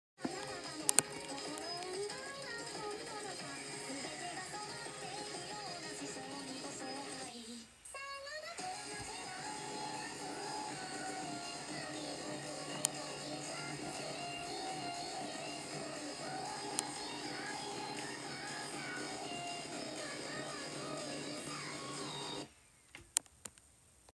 I thought my computer hanging sound effects free download